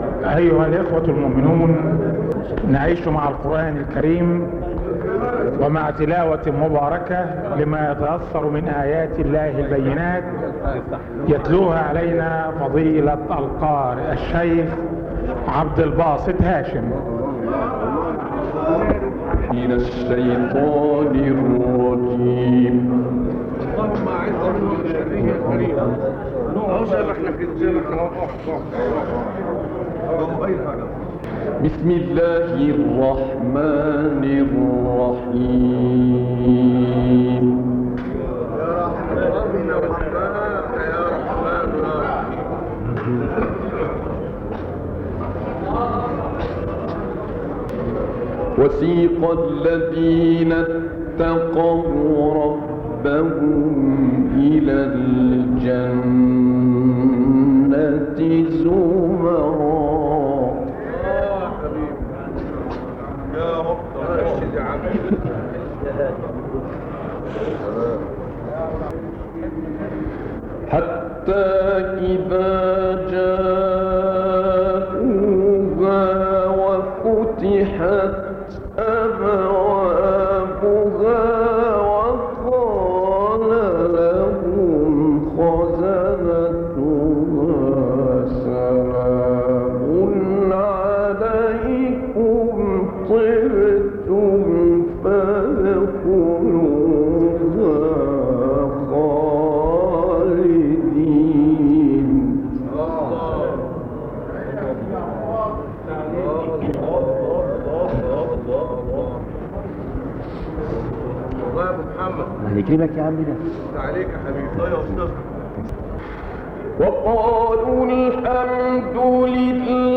تلاوة لما تيسر من سورة الزمر في الاحتفال بالمولد النبوي مسجد فجر الإسلام فترة التسعينيات
الزمر احتفالا بالمولد النبوي فجر الإسلام.mp3